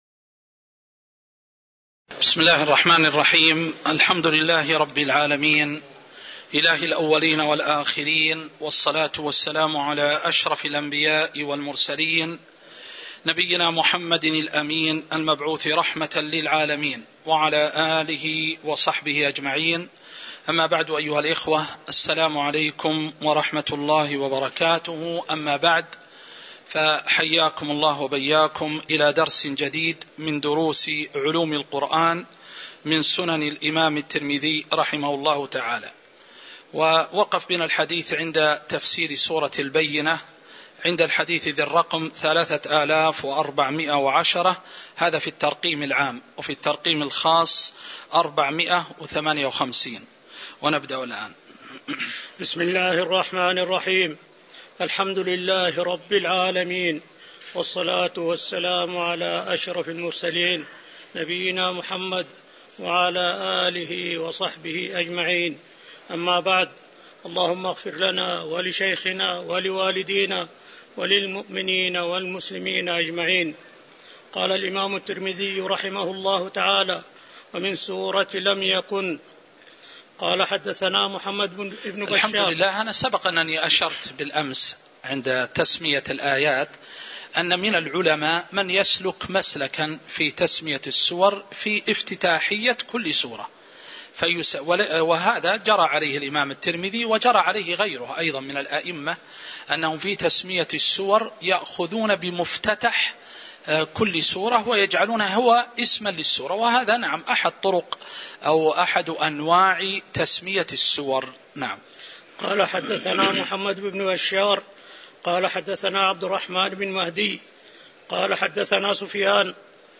تاريخ النشر ١٠ شعبان ١٤٤٣ هـ المكان: المسجد النبوي الشيخ